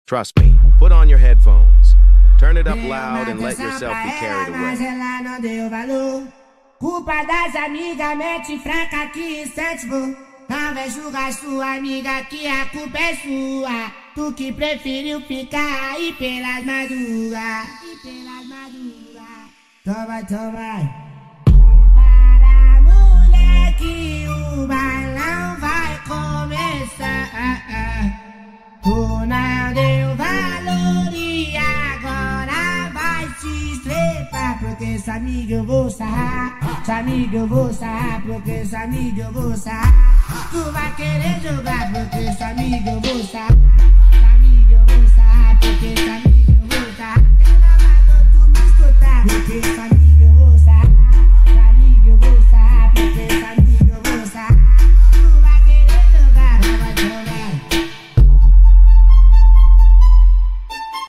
#8daudio #8dmusic